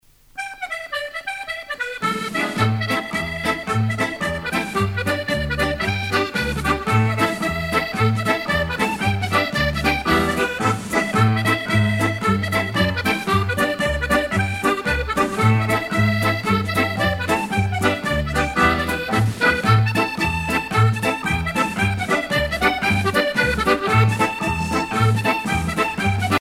danse : marche